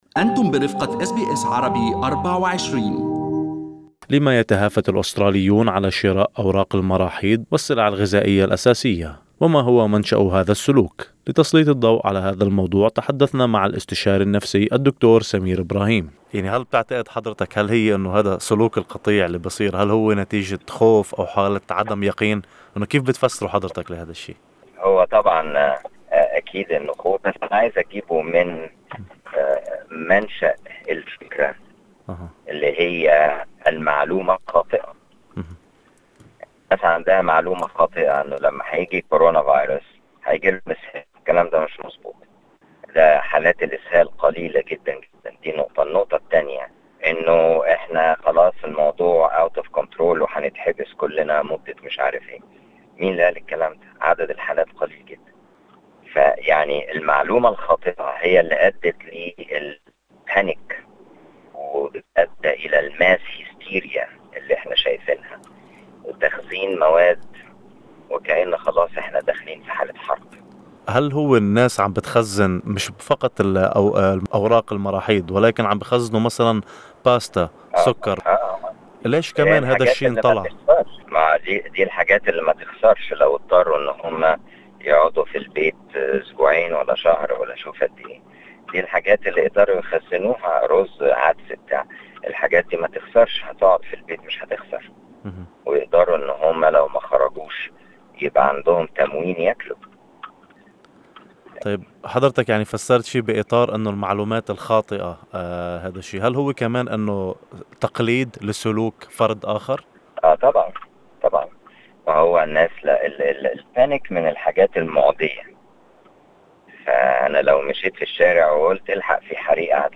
في هذا اللقاء مع الاستشاري النفسي